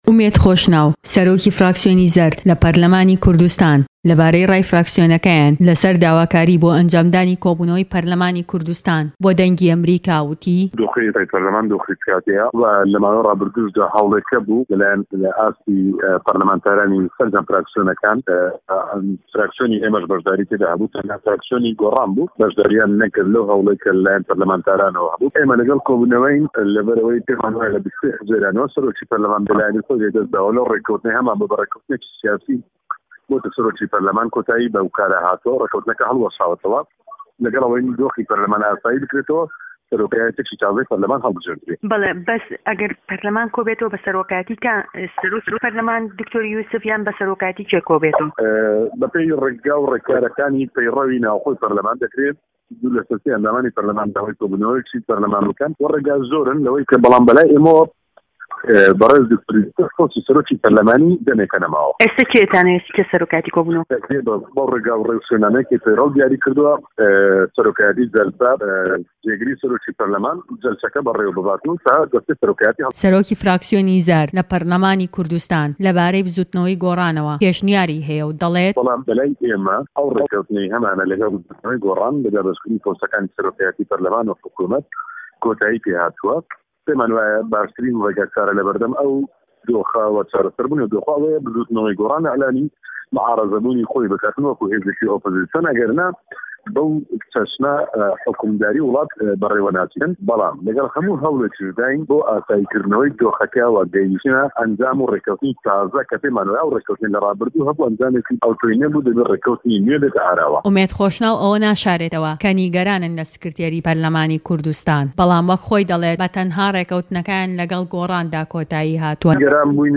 وتووێژ لەگەڵ ئومێد خۆشناو